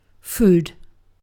food-uk.mp3